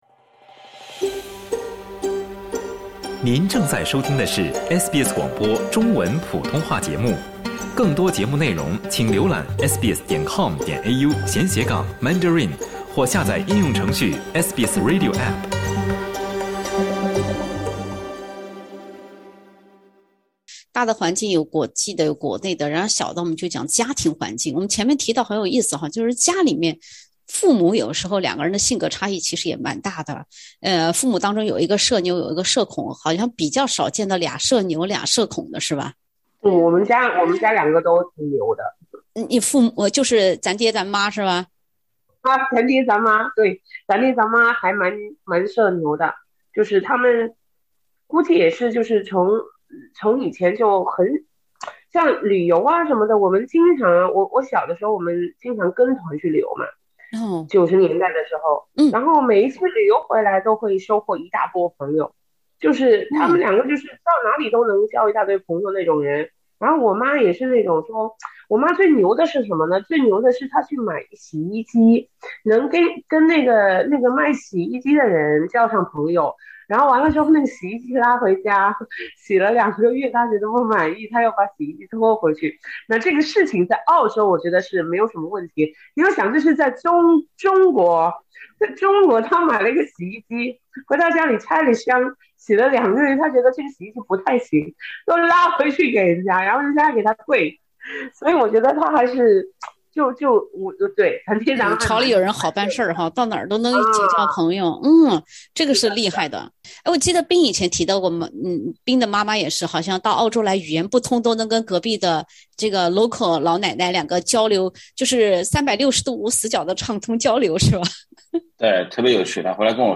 SBS全新谈话类节目《对话后浪》，倾听普通人的烦恼，了解普通人的欢乐，走进普通人的生活。
（点击封面图片，收听风趣对话）